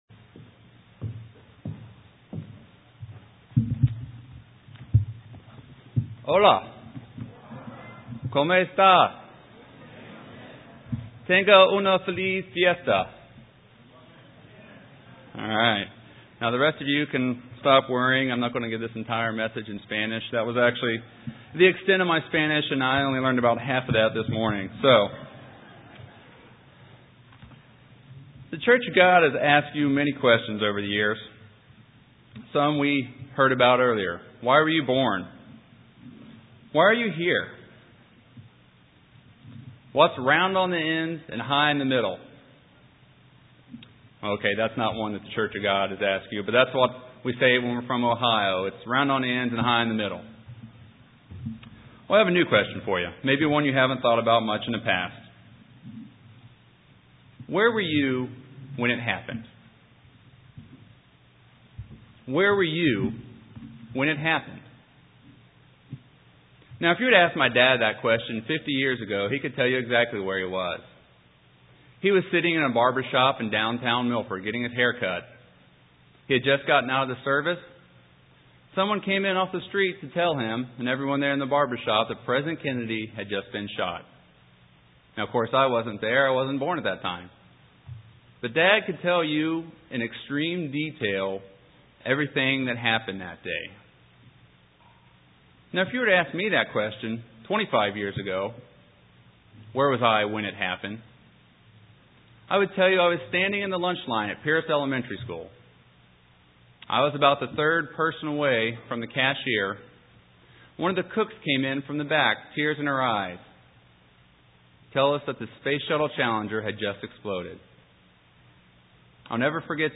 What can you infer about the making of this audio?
Day Six FOT New Braunfels.